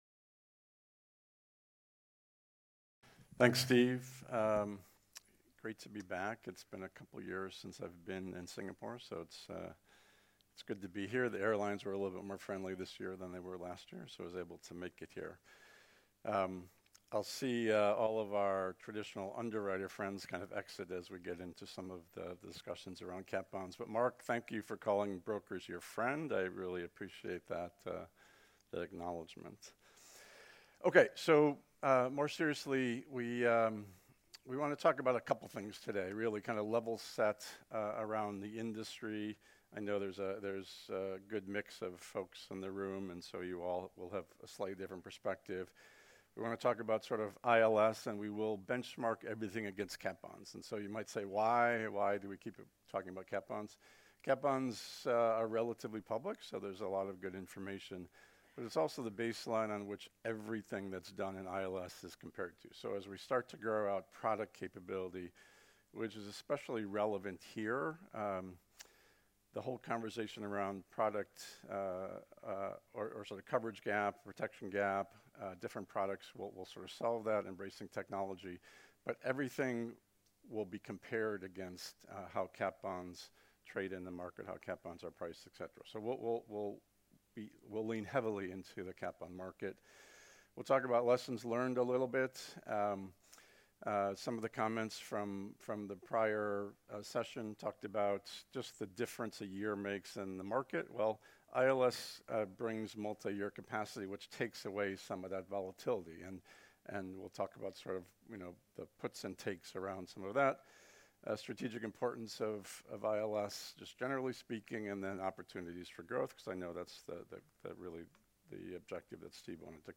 This episode features a speech and fireside chat interview from Artemis' ILS Asia 2024 conference, which was held in Singapore on July 11th. It was our sixth in-person conference in Singapore focused on catastrophe bonds, insurance-linked securities (ILS) and alternative reinsurance capital trends.